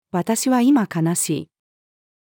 私は今悲しい。-female.mp3